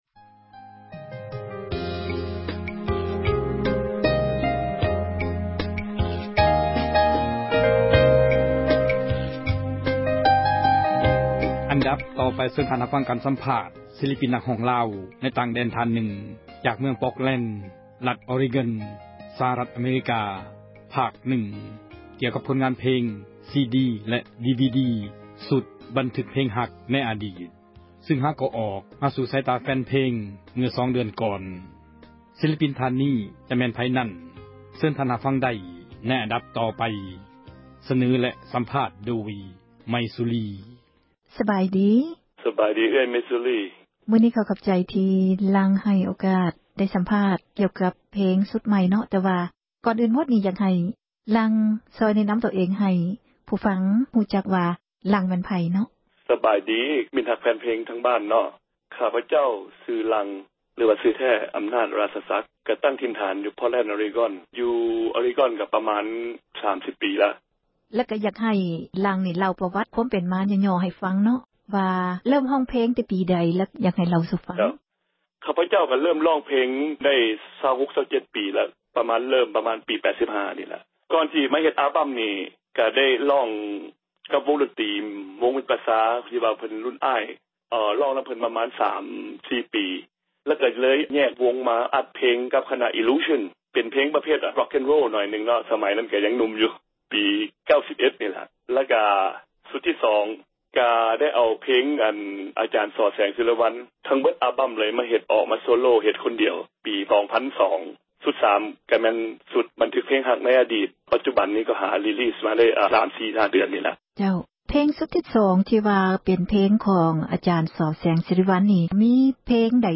ສັມພາດສິລປິນ ນັກຮ້ອງລາວ